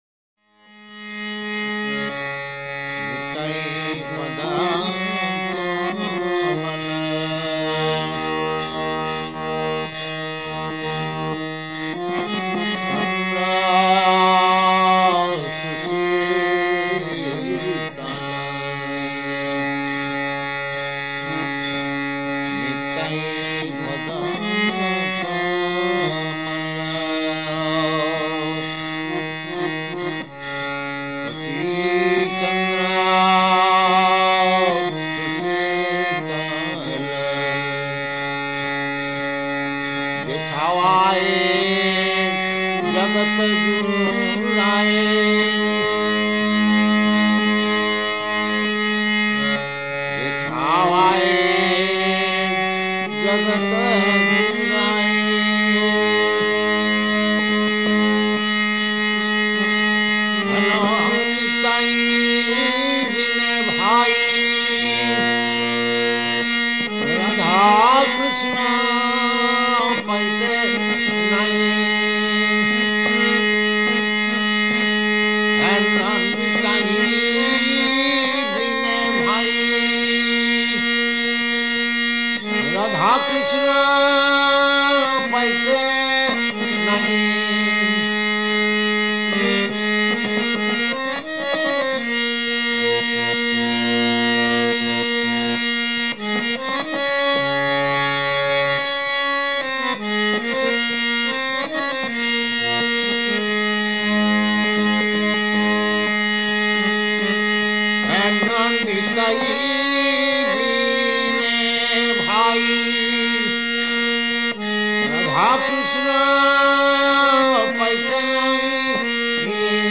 Lord Nityānanda Prabhu's Appearance Day Bhajan & Nitāi-Pada-Kamala Purport
Type: Purport
Location: Los Angeles